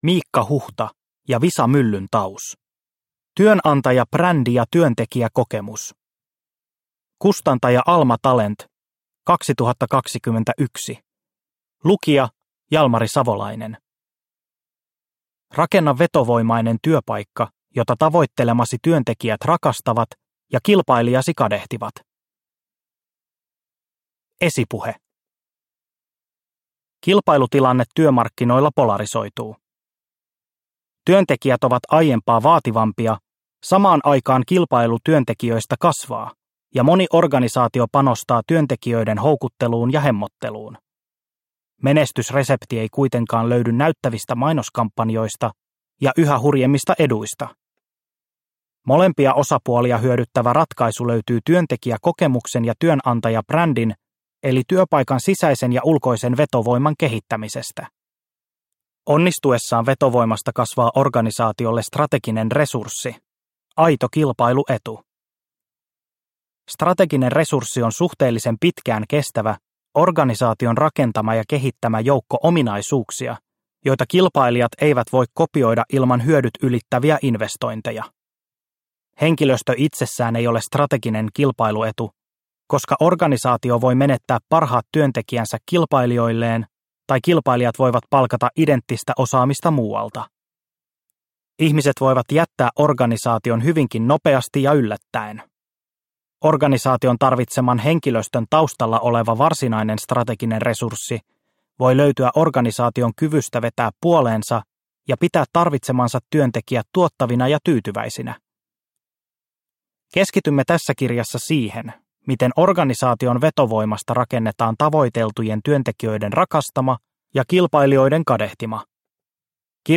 Työnantajabrändi ja työntekijäkokemus – Ljudbok – Laddas ner